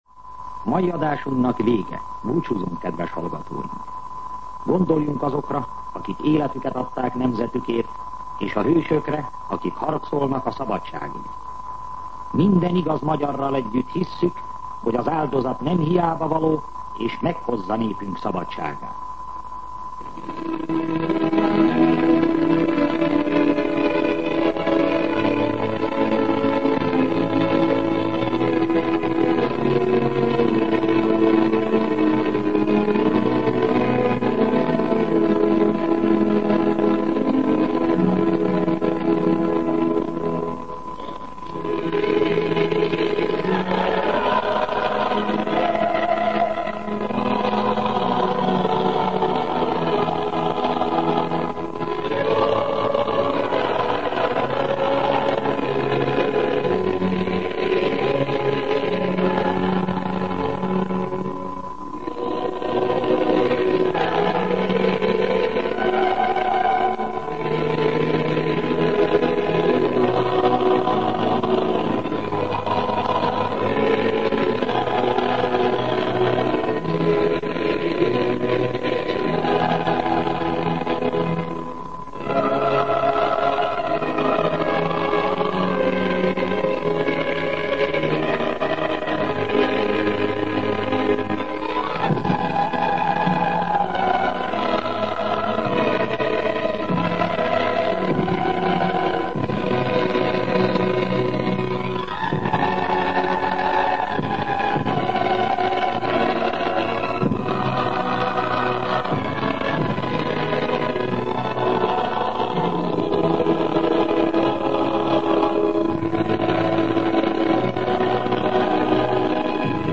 Műsorzárás
Himnusz Információk Adásba került 1956-11-01 1:55 Hossz 0:02:29 Cím Műsorzárás Műsor letöltése MP3